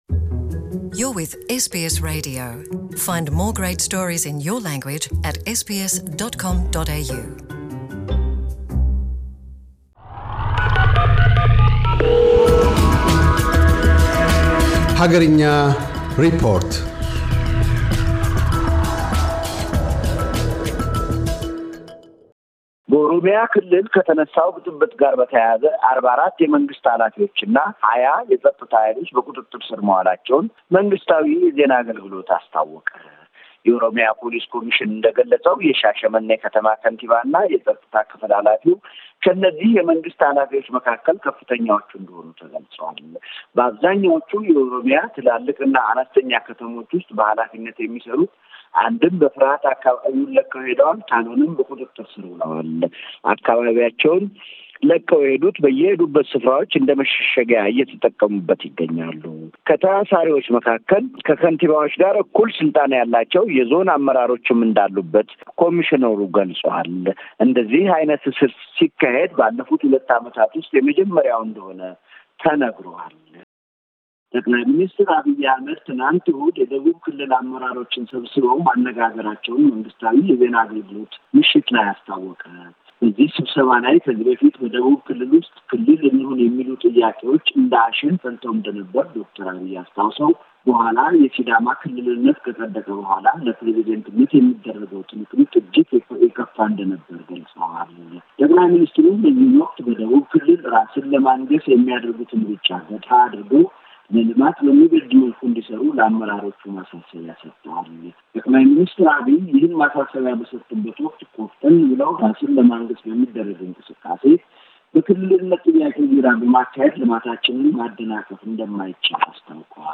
አገርኛ ሪፖርት